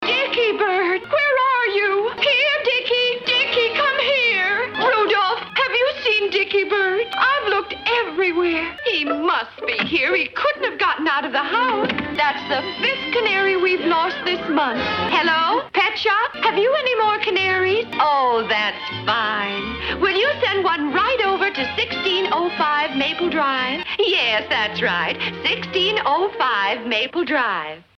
Audio snippets assembled from Puss n' Booty animated cartoon.
puss-n-booty-lady.mp3